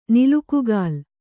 ♪ nilukugāl